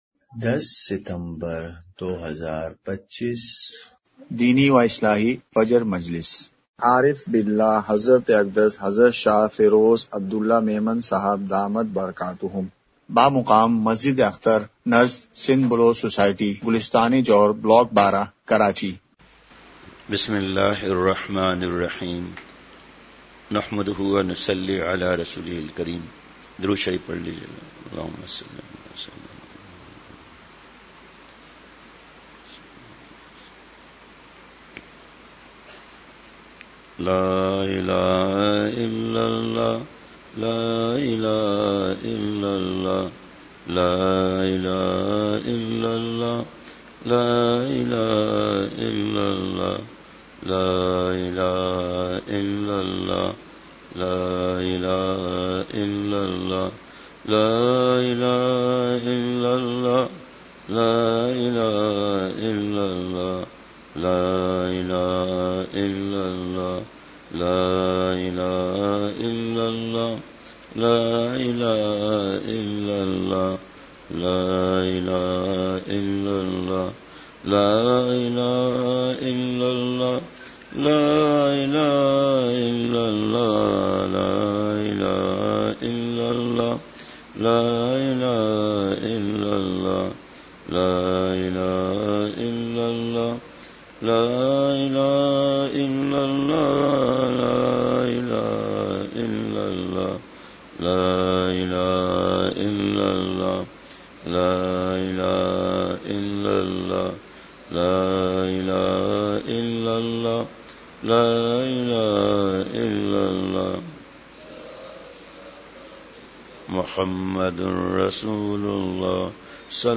اصلاحی مجلس کی جھلکیاں *مقام:مسجد اختر نزد سندھ بلوچ سوسائٹی گلستانِ جوہر کراچی*
مجلسِ ذکر:کلمہ طیّبہ کی ایک تسبیح۔۔۔!!